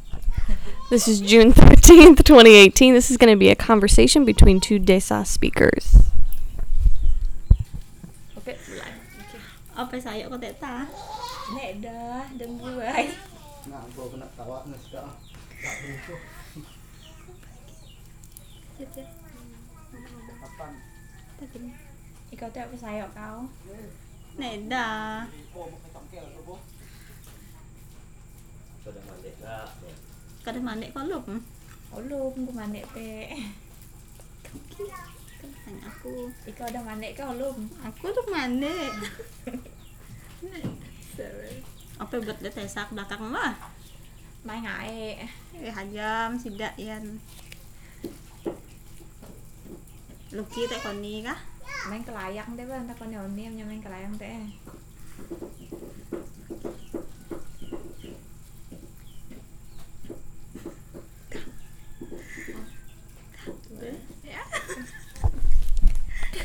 Desa language: Recording of short conversation
Suak Mansi, Sanggau Regency, West Kalimantan, Indonesia; recording made in Sungai Galing, Indonesia